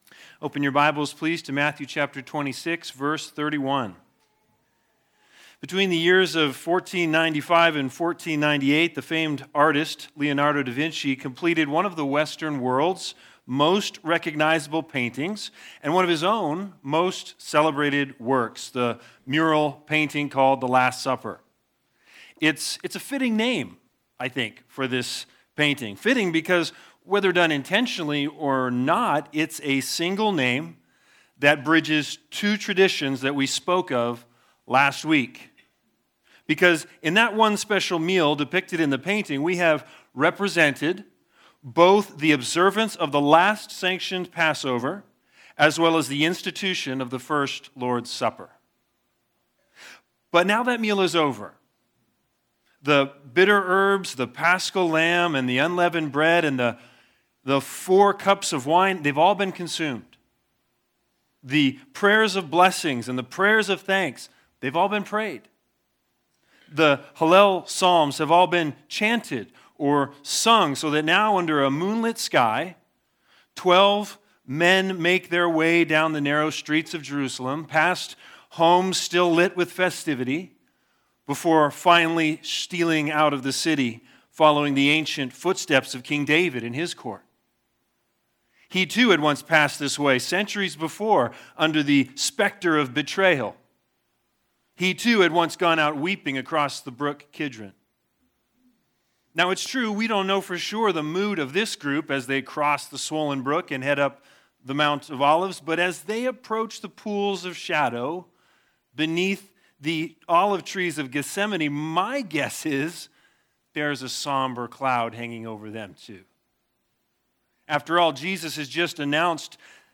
Matthew 26:31-35 Service Type: Sunday Sermons Big Idea